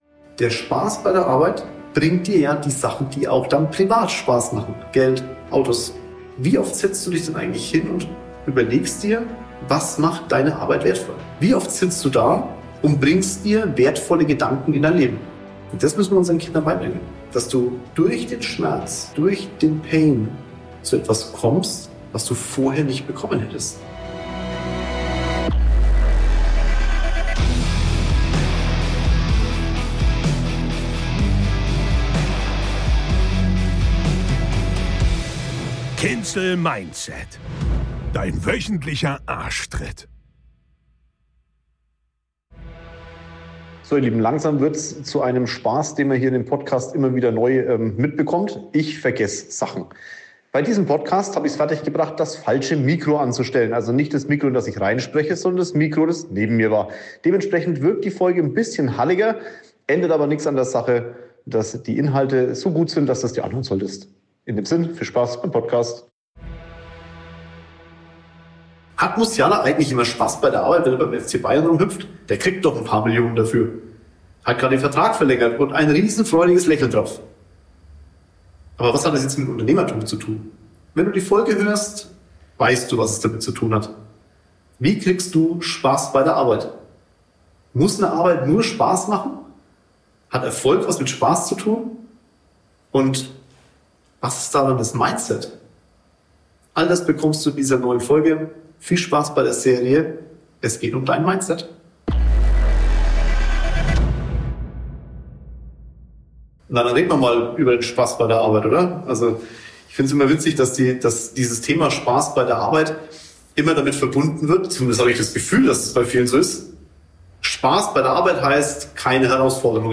Disclaimer: Aufgrund einer kleinen technischen Herausforderungen ist die Tonqualität in dieser Folge nicht ganz optimal.